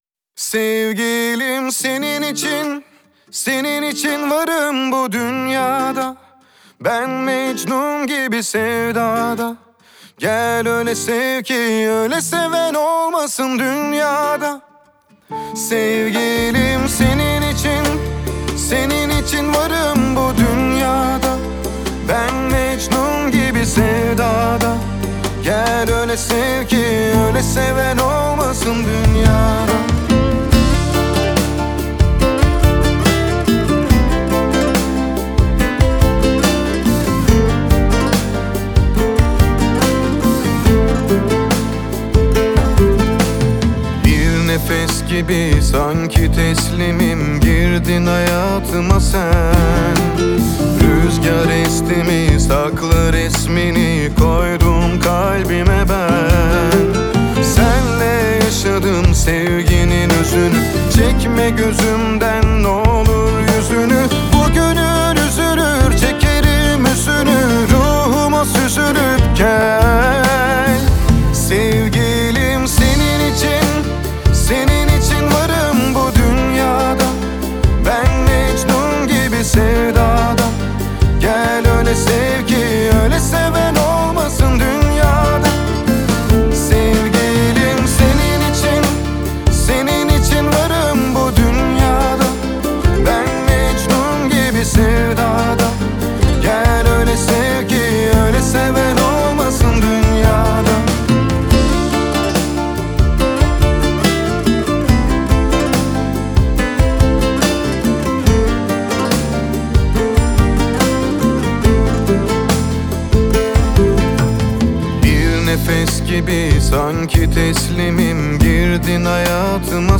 آهنگ ترکی تانگو